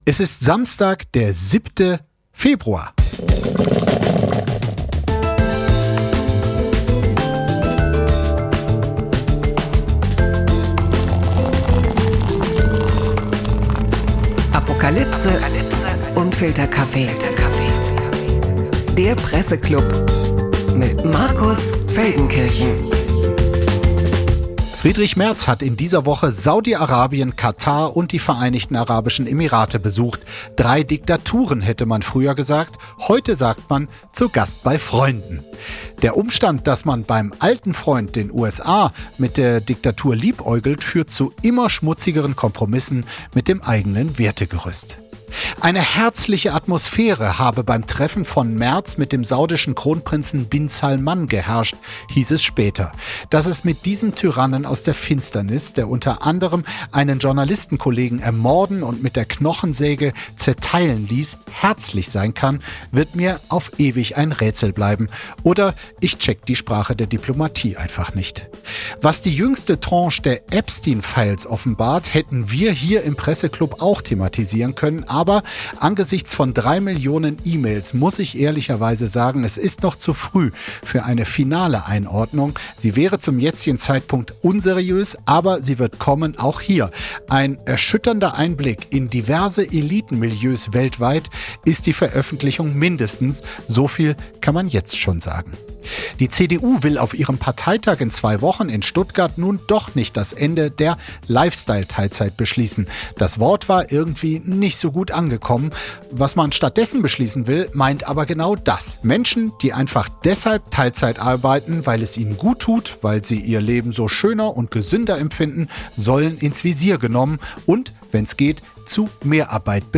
Höchste Zeit, dass auch wir uns im Presseklub einmal die Arbeit machen und diskutieren: Sind wir Deutschen zu faul?